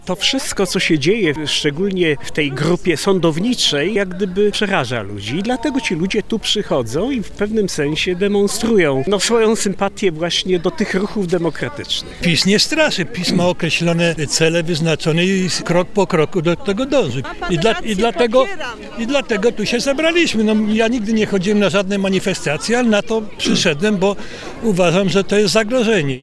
Akcja "Stargard dla demokracji" na Rynku Staromiejskim